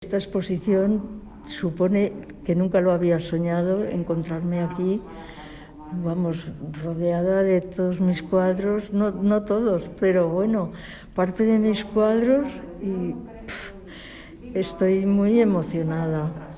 Así lo comentaba formato MP3 audio(0,32 MB) la tarde en que se inauguraba su exposición.